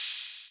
[ACD] - ServeTheStreets Hat (2).wav